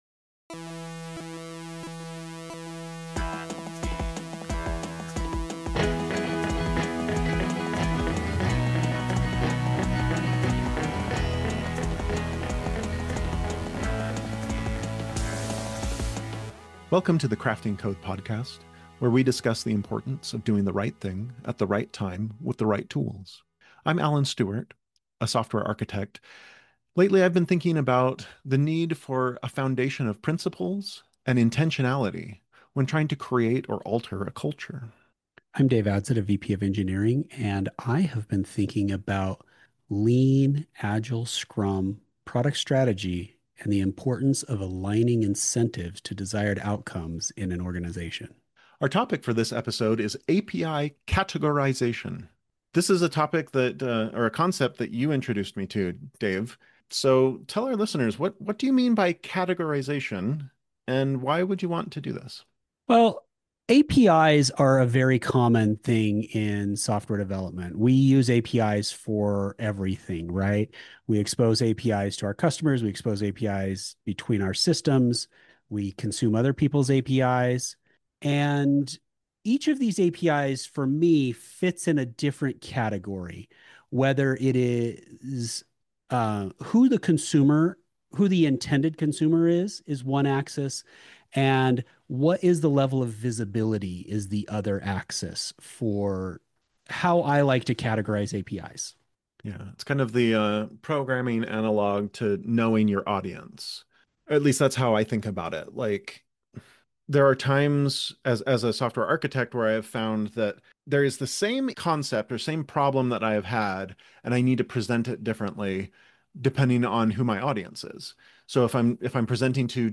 Providing separate APIs for different purposes can make each one much easier to manage, especially when it comes time to roll out updates. In this episode, your hosts discuss how we like to categorize our APIs, how we deal with versioning, and why you might want to follow these patterns too.